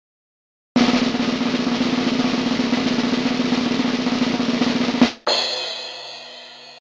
DRUM ROLL - sound effect
Category: Sound FX   Right: Personal